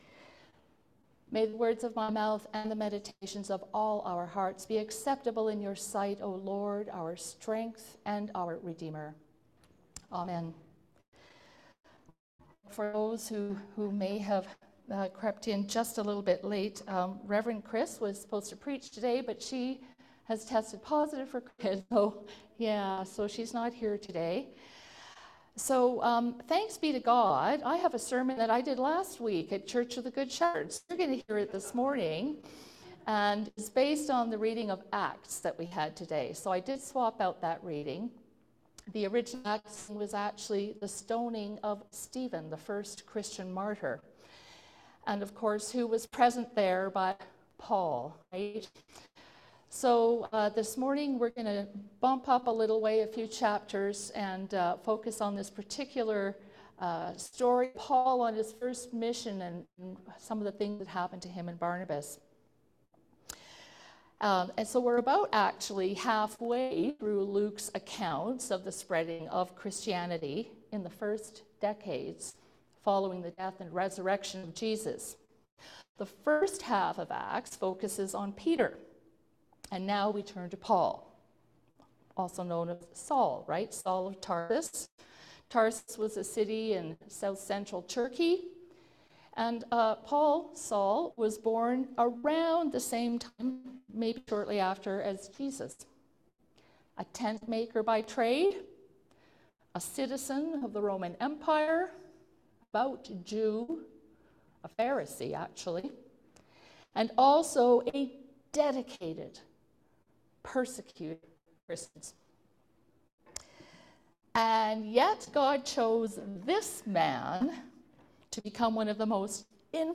Sermons | St. George's Anglican Church
Zoom was a little glitchy this morning so the recorded audio is not a clear as usual.